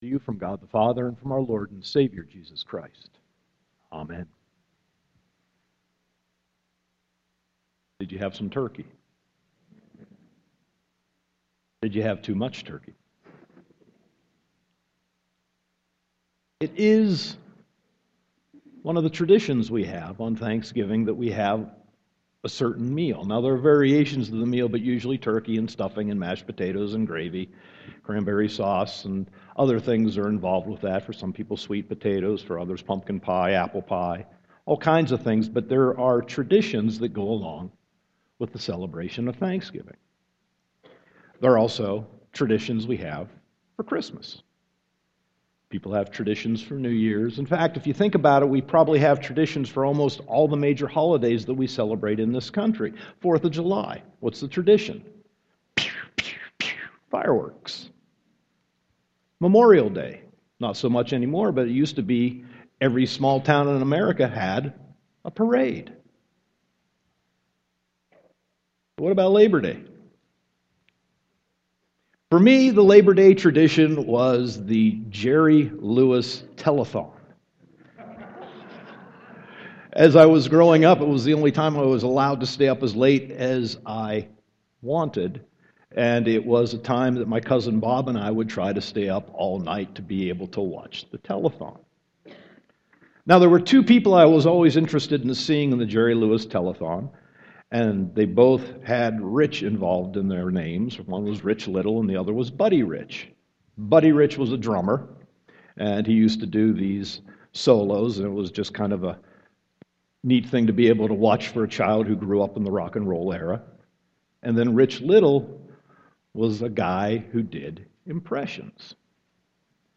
Sermon 11.30.2014